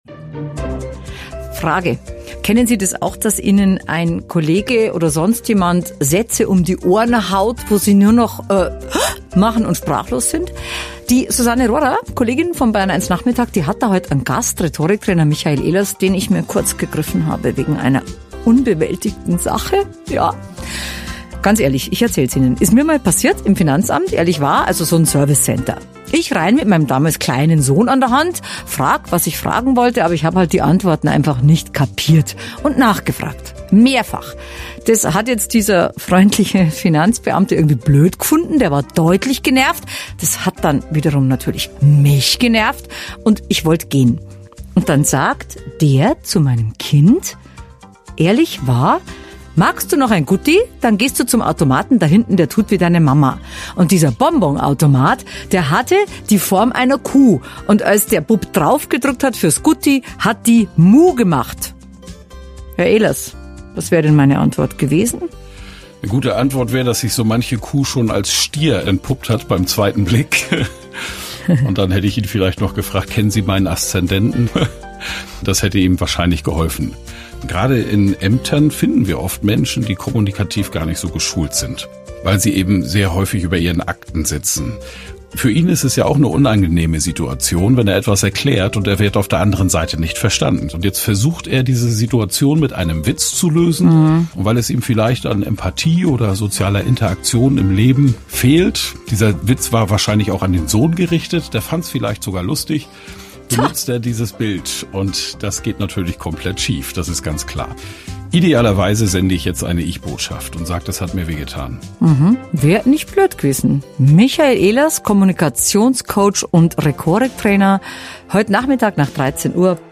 Wortmächtig statt Schlagfertig - Bayern1 Radio-Interview